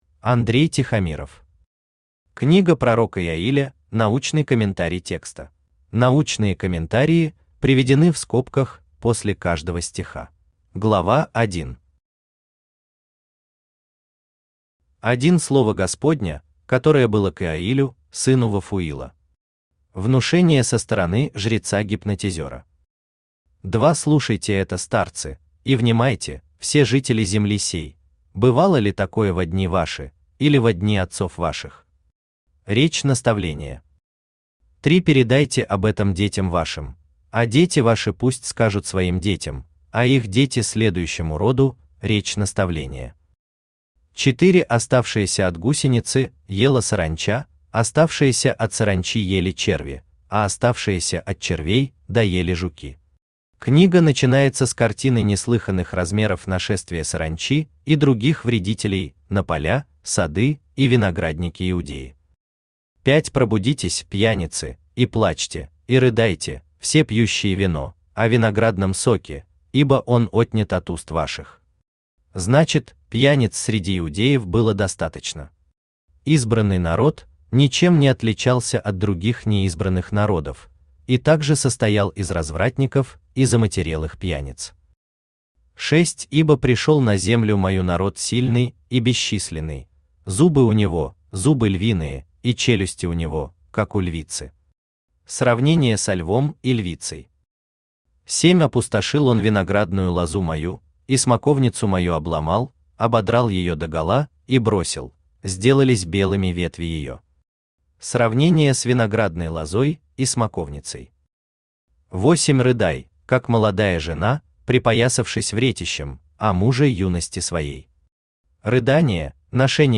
Аудиокнига Книга пророка Иоиля – научный комментарий текста | Библиотека аудиокниг
Читает аудиокнигу Авточтец ЛитРес.